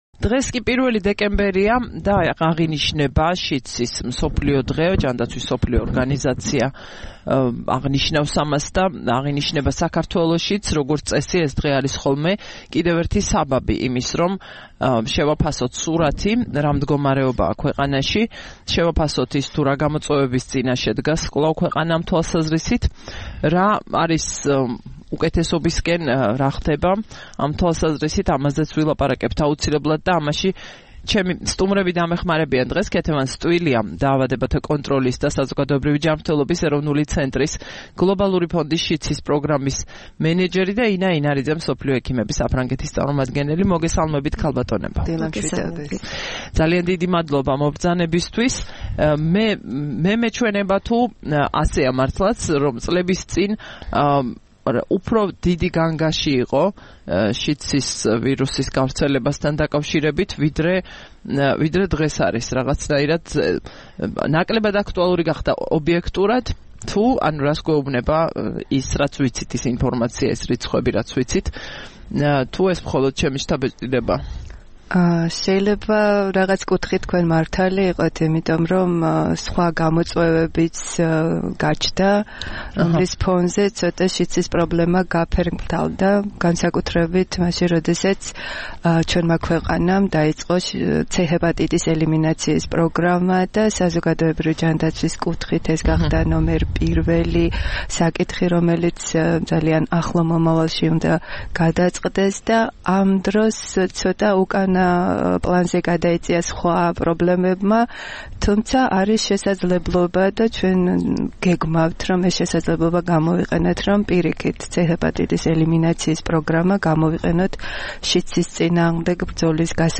1 დეკემბერს რადიო თავისუფლების "დილის საუბრების" სტუმრები იყვნენ: